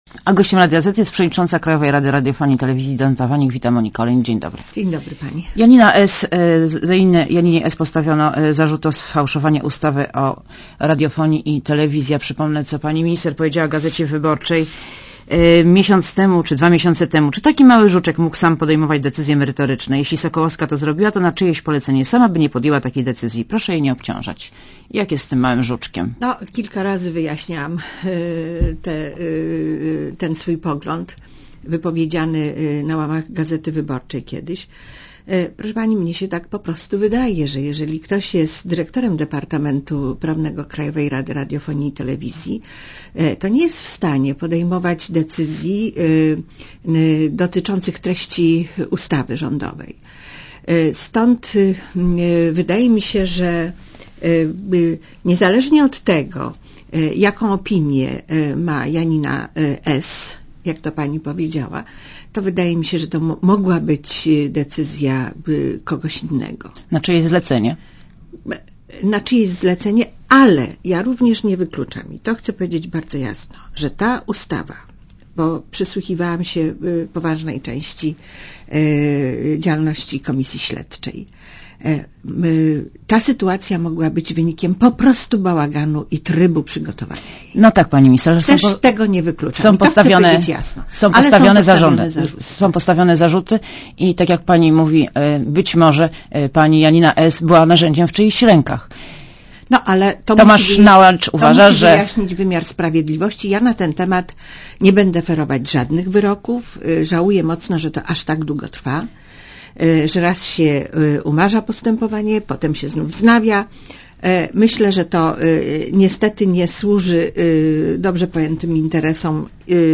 Gościem Radia Zet jest przewodnicząca KRRiTv, Danuta Waniek.